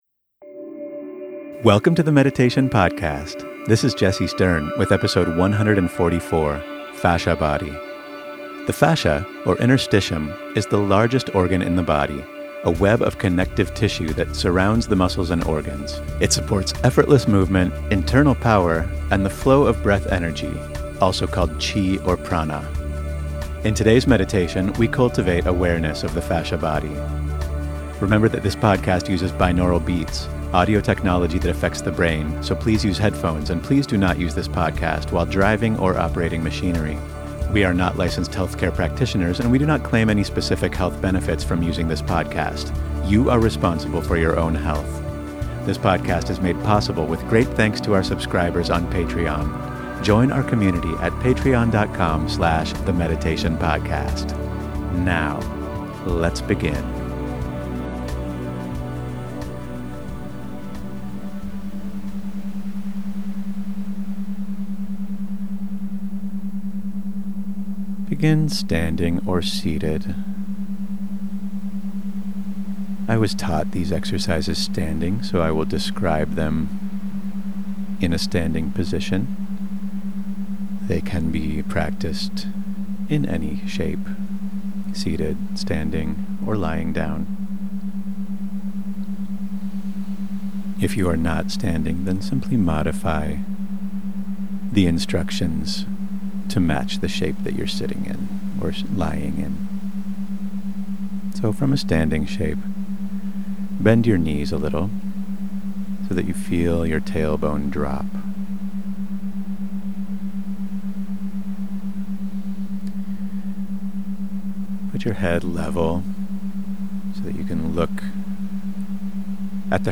------- Remember that this podcast uses binaural beats, audio technology that affects the brain, so please use headphones, and please do not use this podcast while driving or operating machinery.